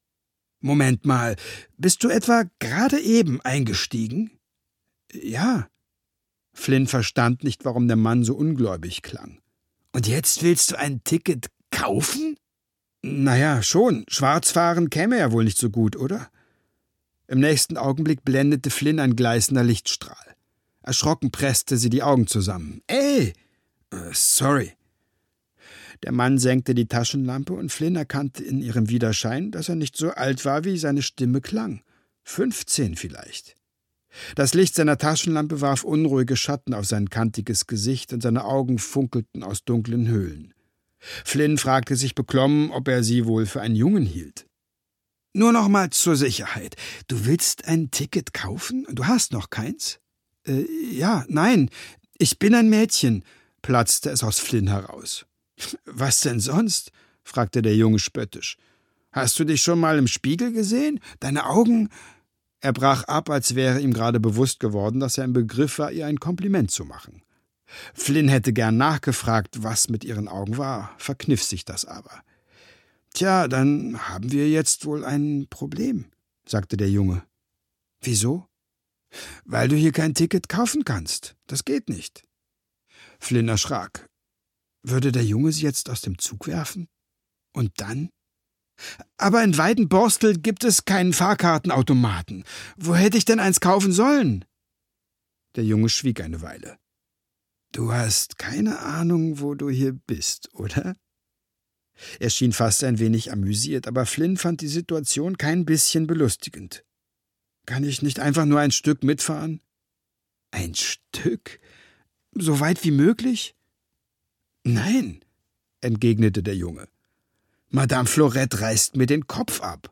Der Welten-Express (Der Welten-Express 1) - Anca Sturm - Hörbuch